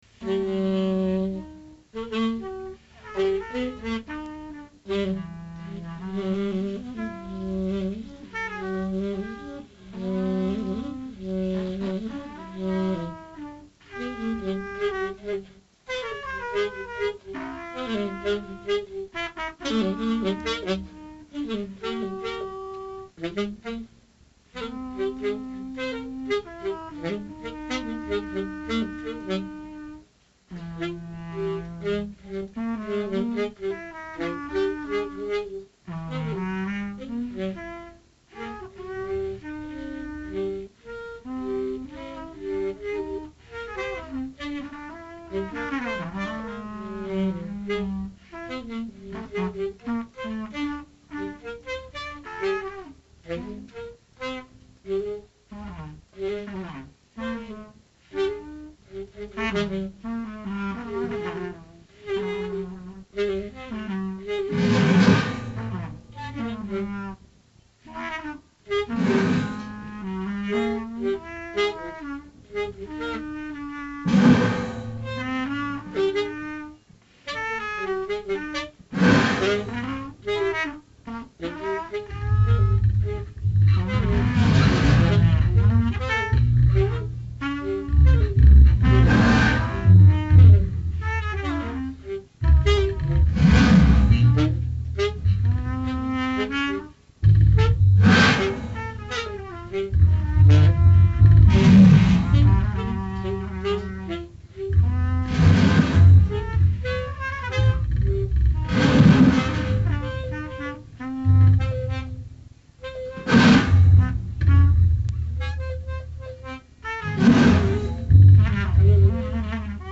various tapes - collaborations - demo recordings
trumpet
bass
metal percussion
noise,alto sax